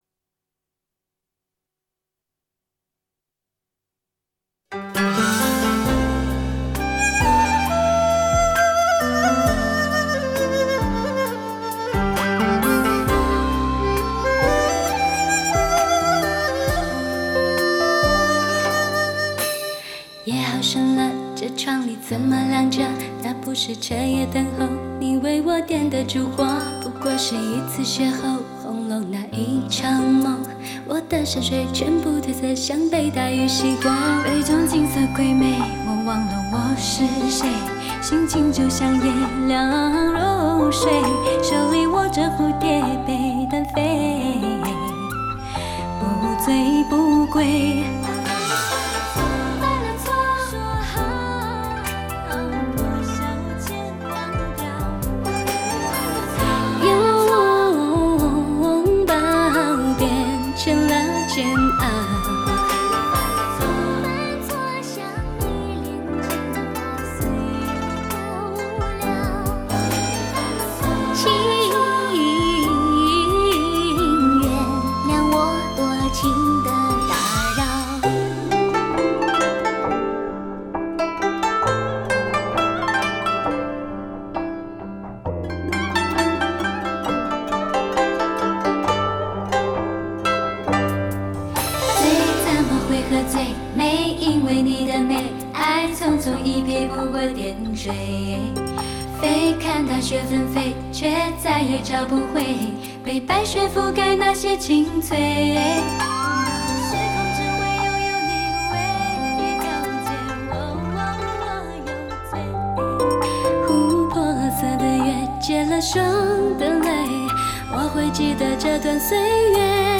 夏天清凉了，琵琶、古筝、竹笛预约山清水秀。